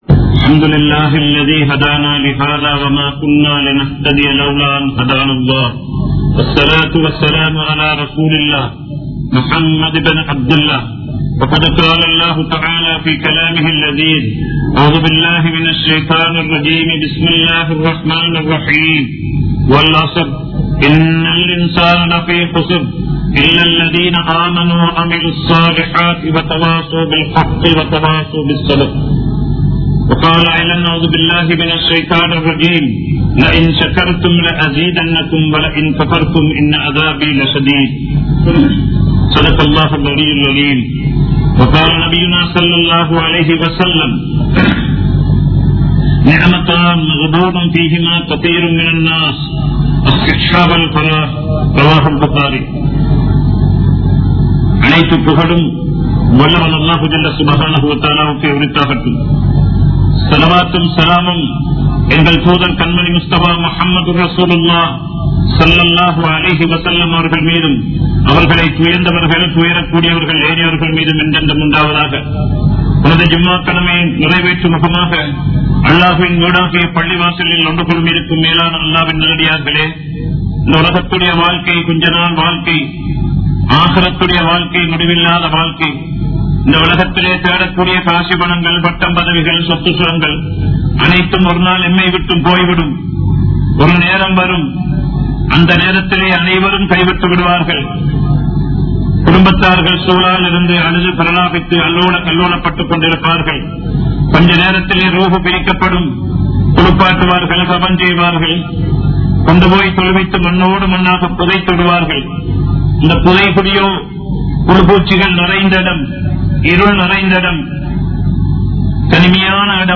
Nerathin Perumathi (நேரத்தின் பெறுமதி) | Audio Bayans | All Ceylon Muslim Youth Community | Addalaichenai
Kollupitty Jumua Masjith